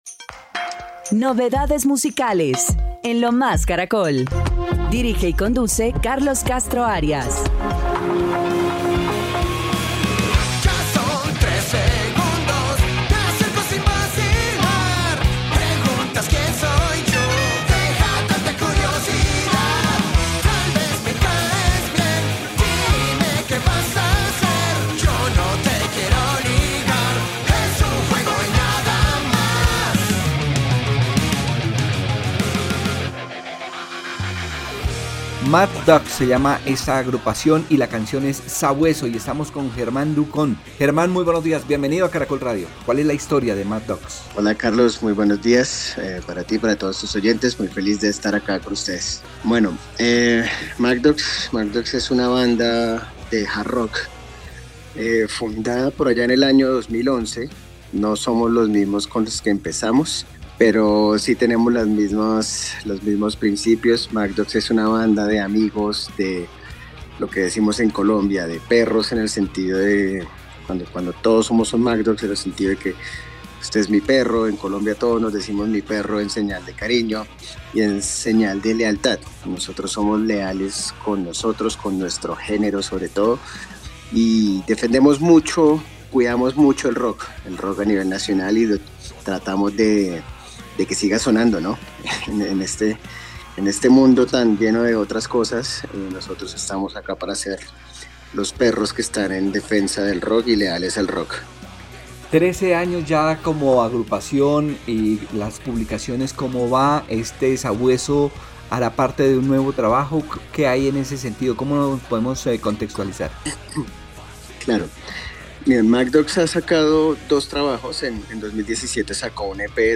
guitarrista y segunda voz